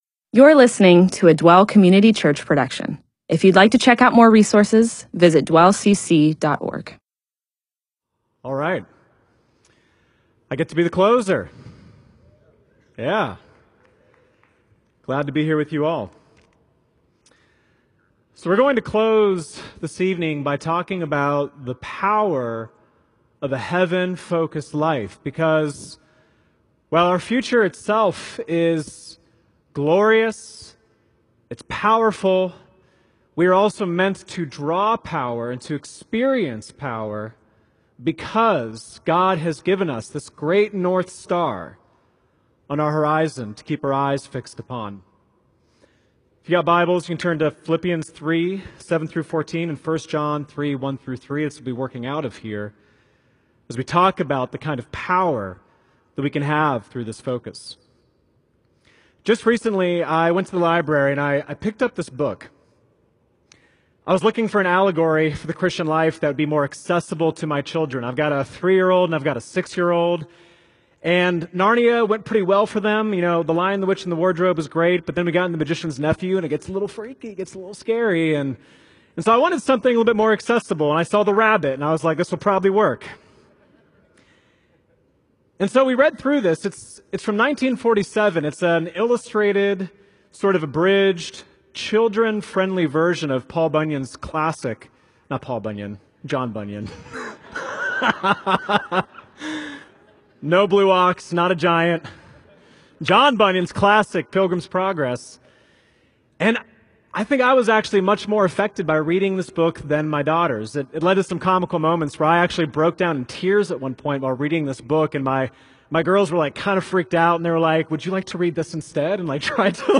MP4/M4A audio recording of a Bible teaching/sermon/presentation about Philippians 3:7-14.